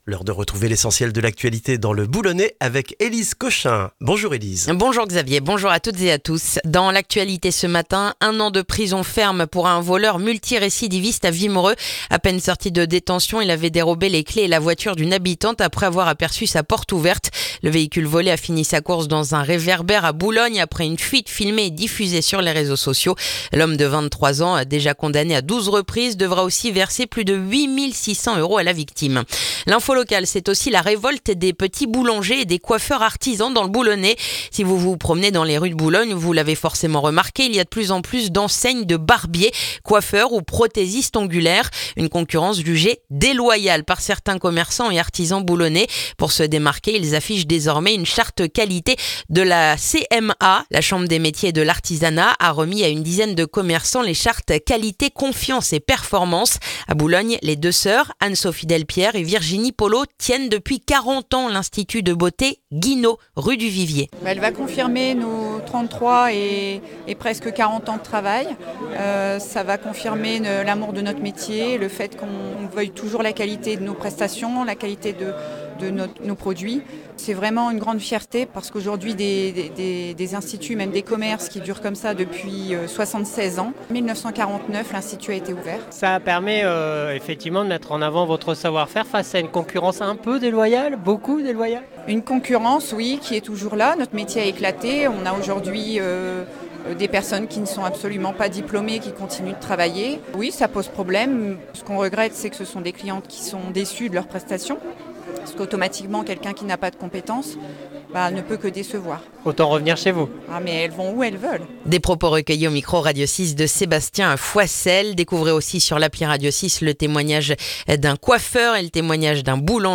Le journal du mercredi 19 novembre dans le boulonnais